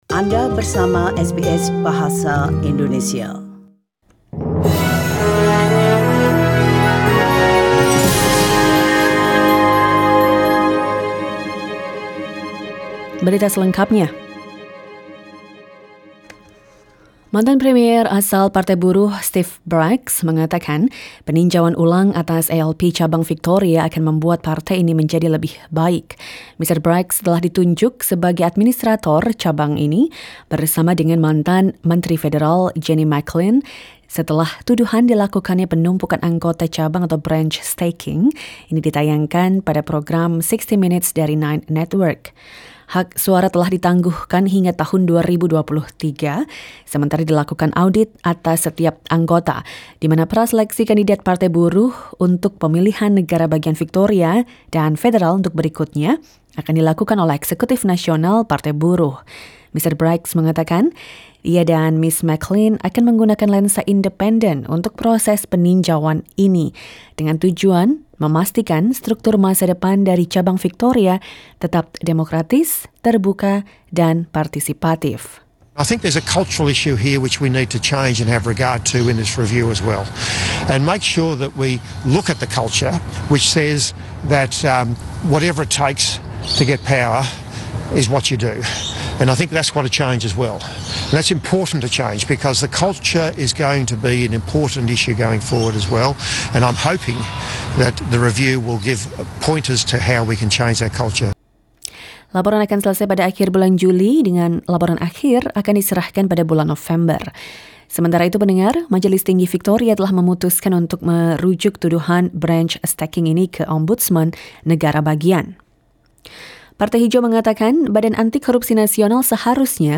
SBS Radio news in Indonesian - 17 June 2020